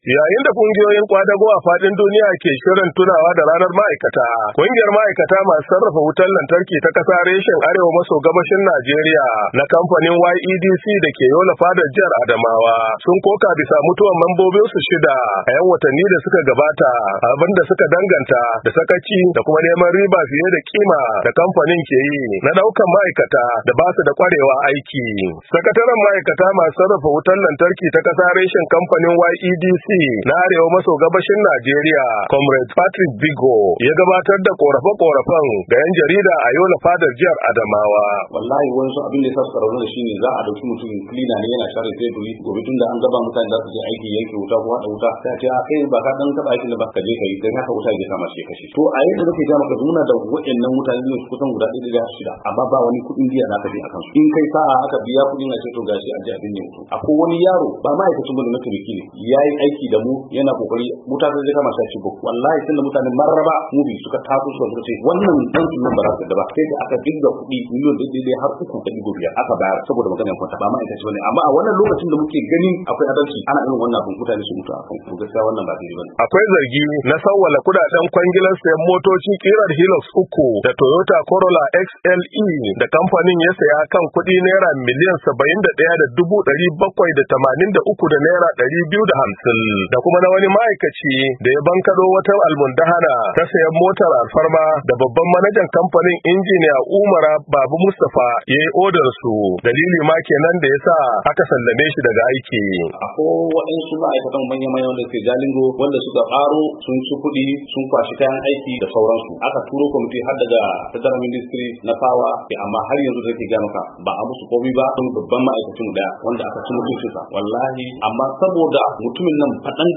Saurari rahotan